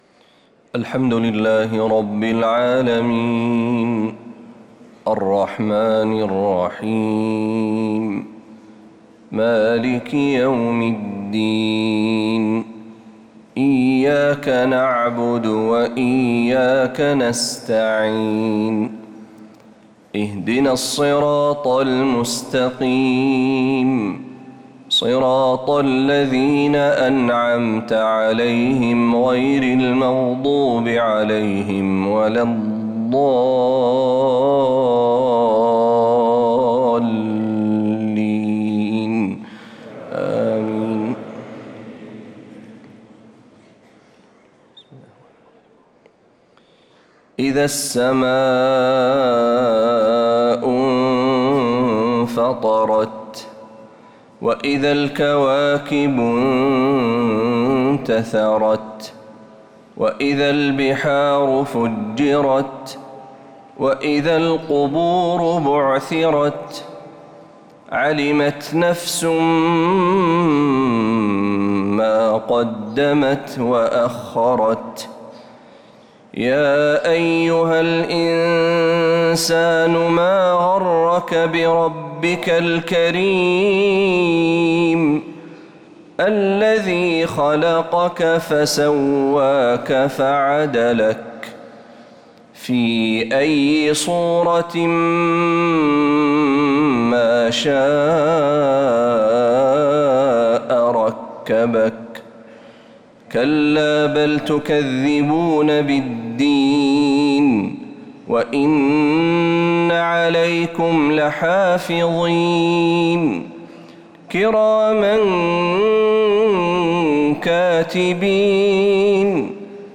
فجر الإثنين 6-7-1446هـ سورتي الإنفطار و الزلزلة كاملة | Fajr prayer from Surat al-Infitar & Az-Zalzala 6-1-2025 > 1446 🕌 > الفروض - تلاوات الحرمين